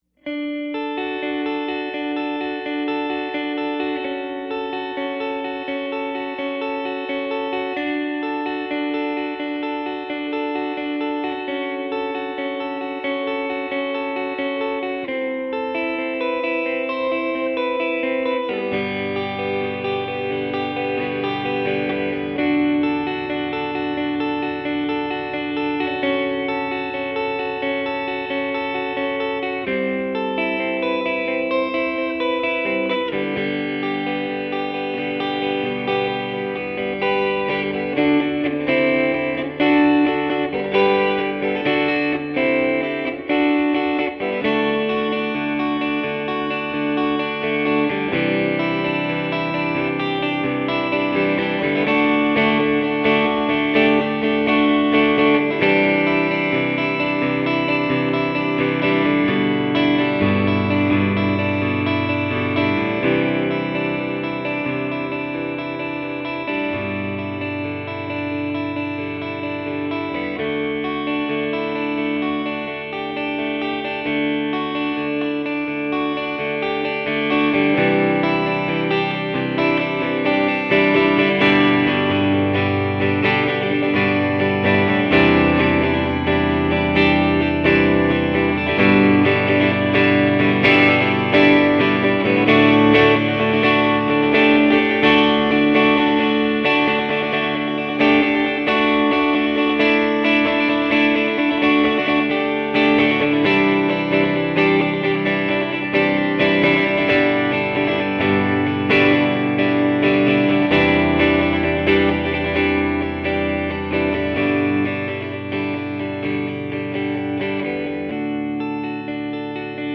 (melodic, nostalgic, melancholy)